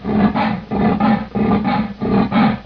На этой странице собраны звуки гепарда в естественной среде обитания: от грозного рыка до нежного мурлыканья детенышей.
Шепот дыхания гепарда